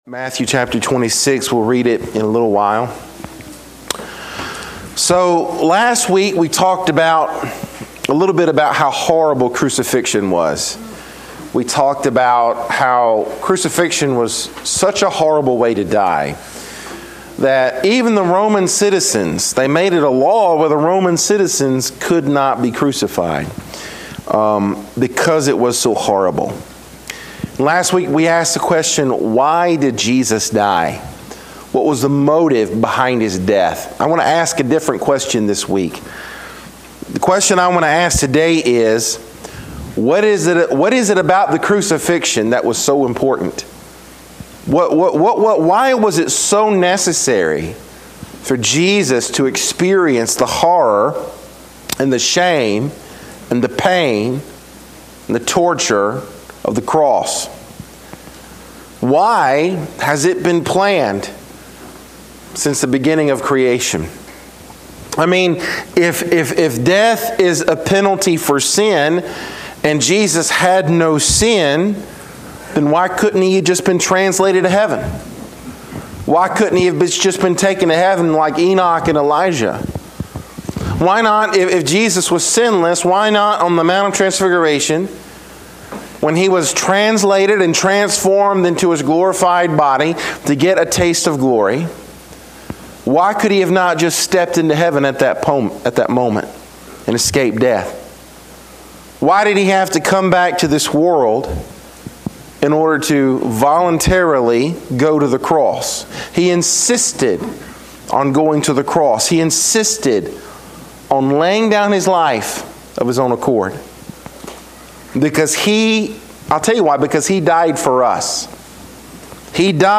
Sermons | Rocky Point Baptist Church